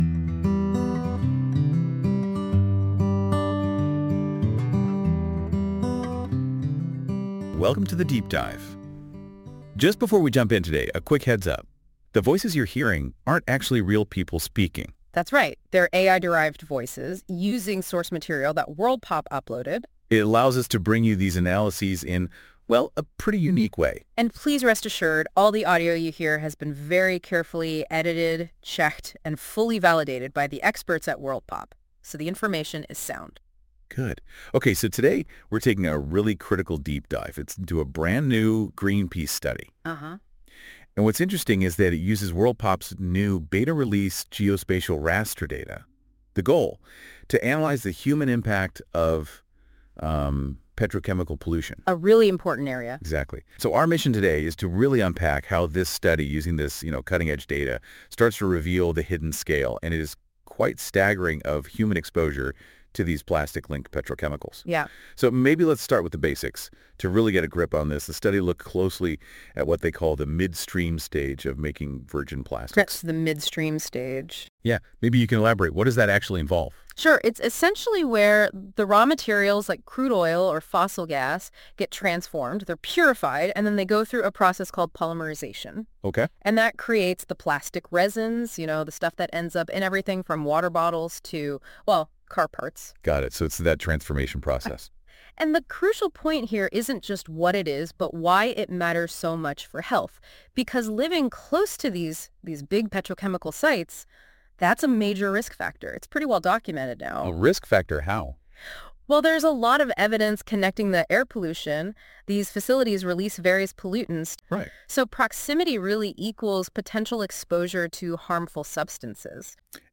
Greenpeace International use of WorldPop data - audio summary
This feature uses AI to create a podcast-like audio conversation between two AI-derived hosts that summarise key points of a document - in this case the Disappearing People article in Science.
Music: My Guitar, Lowtone Music, Free Music Archive (CC BY-NC-ND)